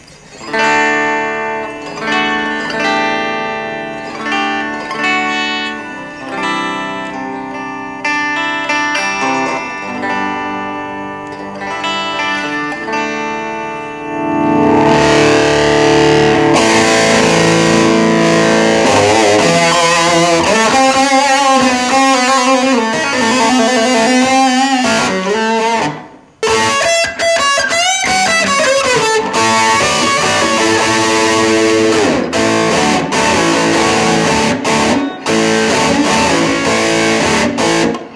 AC30のノーマルチャンネルのフルボリュームというのがどのぐらいの歪み量なのかわからないが、だいたいのところでやってみるとかなりゲインが高くて激しく歪む。レスポールで弾いているわりに歪み方は似ている気もする。ボリュームを絞ってみるとキラキラ感があって、これはギターを替えればもう少し似るはず。